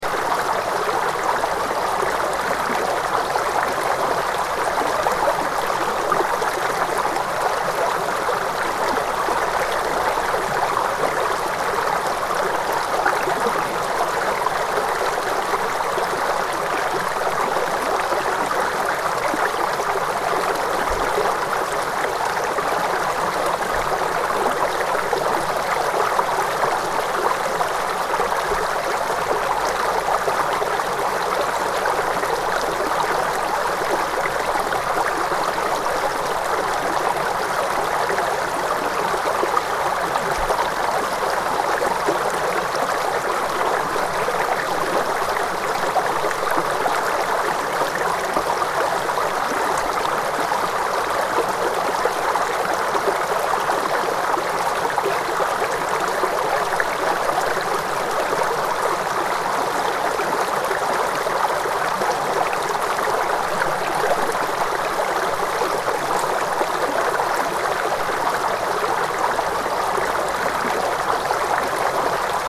Flowing Water Sound Effect: Relaxing Ambiance for Meditation
Babbling brook sounds. Gentle brook streaming in nature – relaxing sound perfect for background ambiance, meditation, exercise, or commercial use in multimedia projects. Audio loop.
Flowing-water-sound-effect.mp3